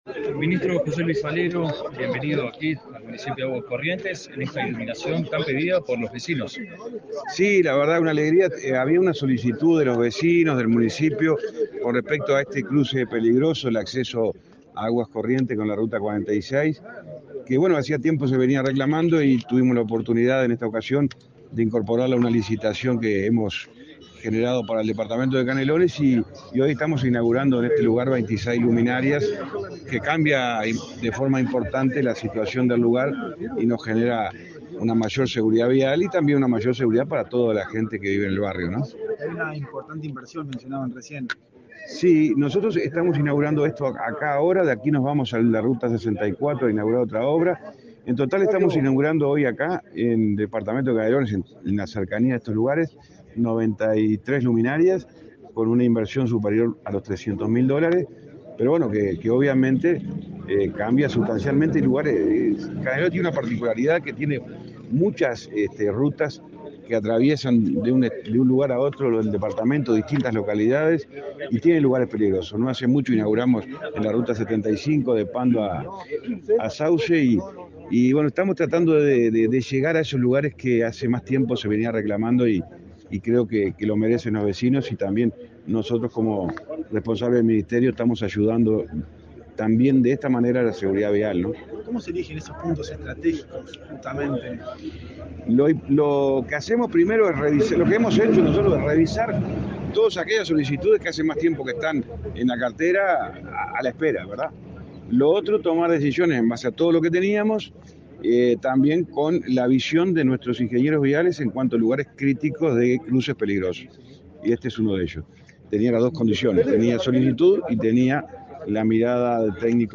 Declaraciones a la prensa del ministro de Transporte y Obras Públicas, José Luis Falero
Tras participar en la inauguración de luminarias en la ruta n.° 46, km.55.800 y Camino de los Ingleses, en el departamento de Canelones, el ministro